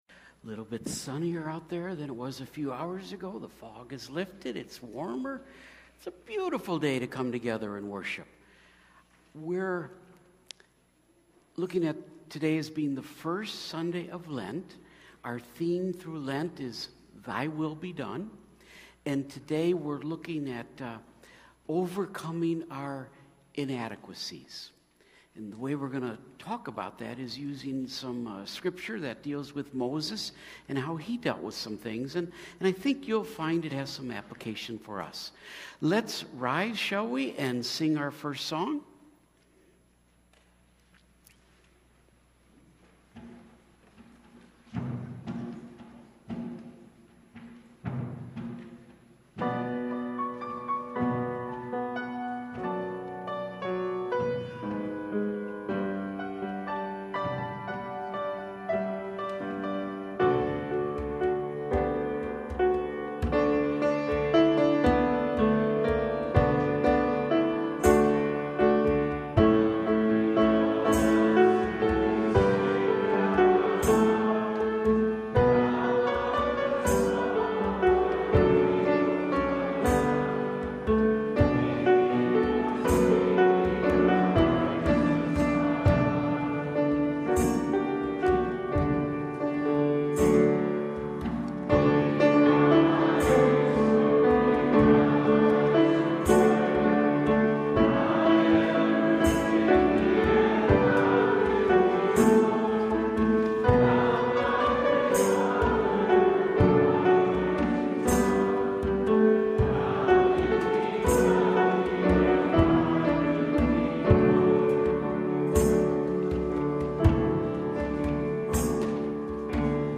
Feb 18 / Worship & Praise – Avoidance, Confidence and Dependence on Jesus – Lutheran Worship audio